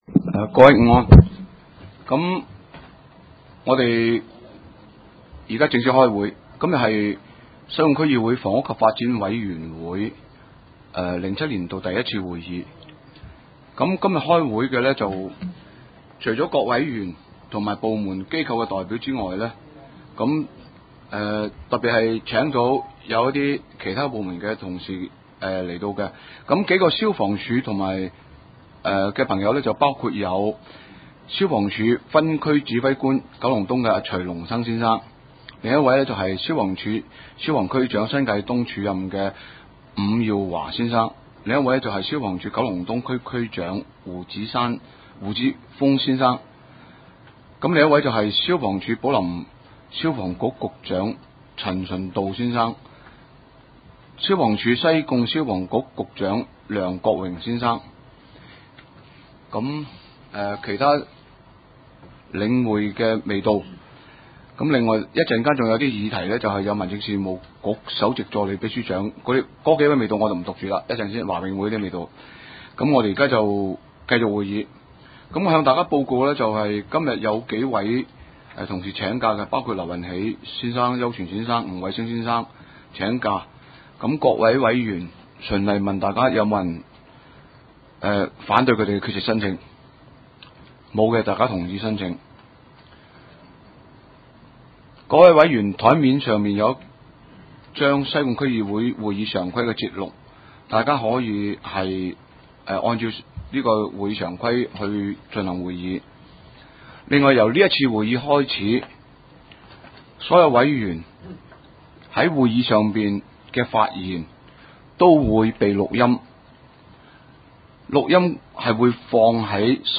地點：西貢區議會會議室